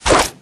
用鱼攻击音效